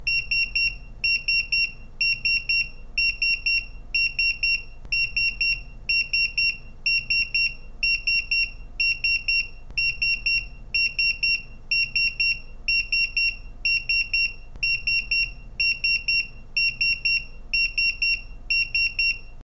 Kategori Mobiltelefon